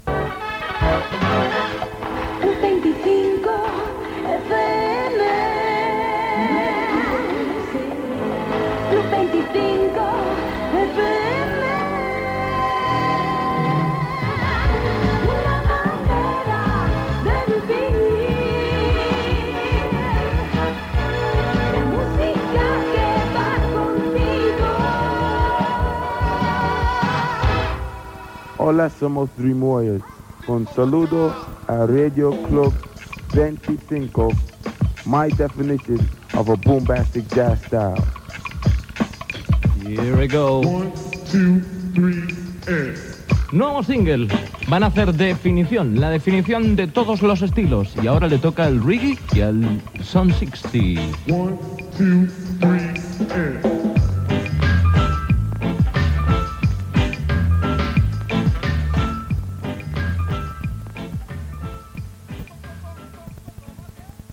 b814ead52ece32ef3848d28a87f75b736a53ff42.mp3 Títol Radio Club 25 Emissora Radio Club 25 Titularitat Privada local Descripció Indicatiu, salutació del grup Dream Warriors i presentació d'un tema musical.